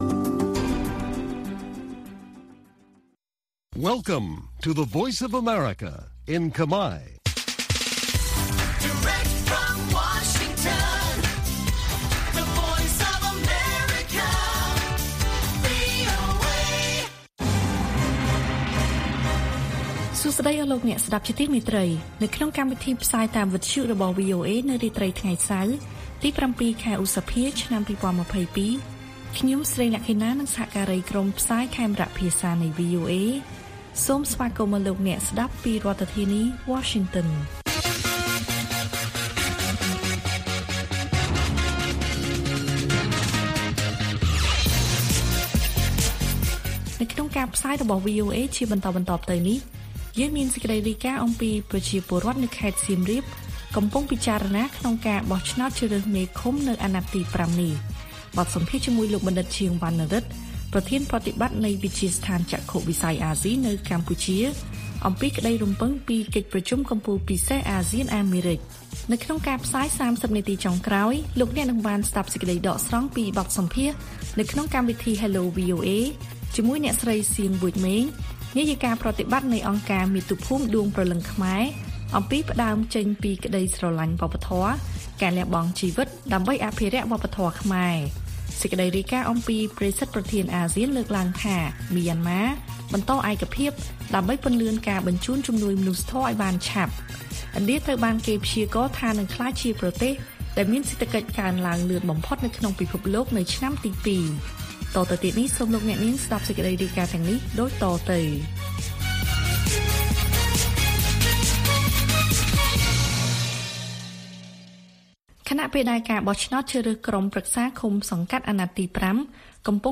ព័ត៌មានពេលរាត្រី ៧ ឧសភា៖ ប្រជាពលរដ្ឋនៅខេត្តសៀមរាបកំពុងពិចារណាក្នុងការបោះឆ្នោតជ្រើសរើសមេឃុំនៅអាណត្តិទី ៥ នេះ